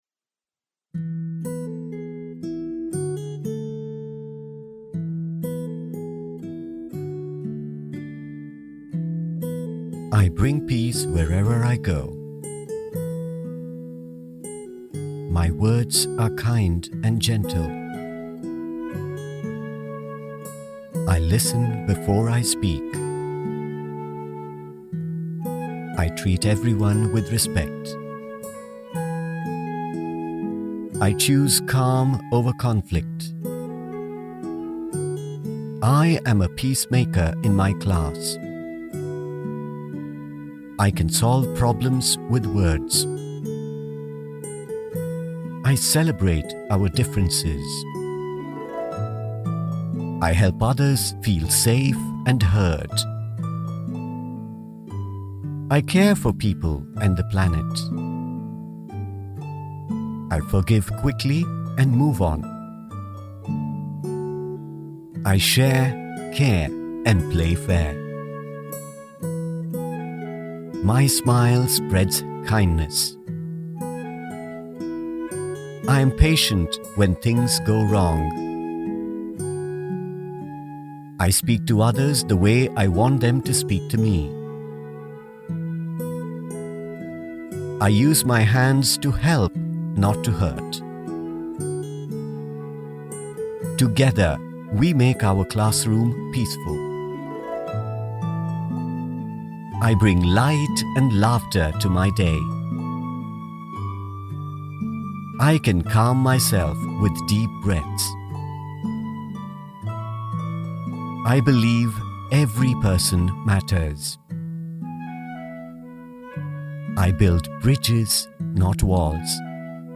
Male Voice Over Talent, Artists & Actors
Adult (30-50) | Older Sound (50+)